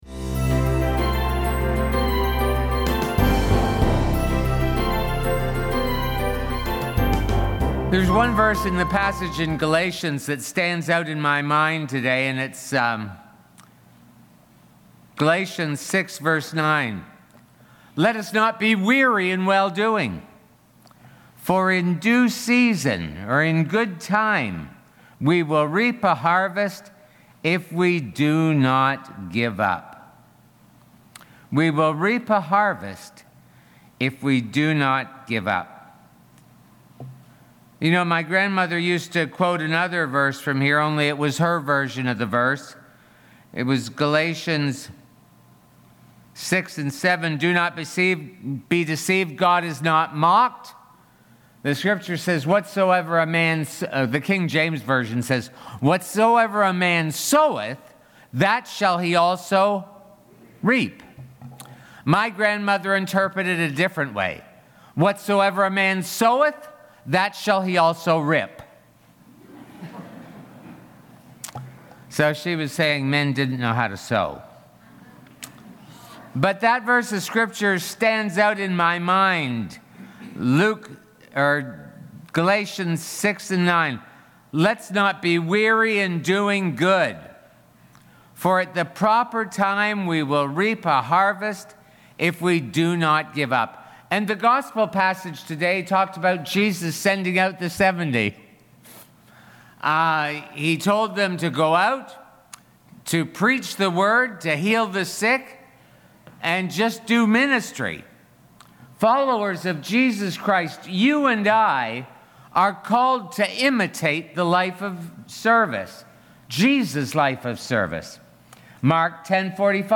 St Paul Sermon Series